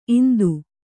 ♪ indu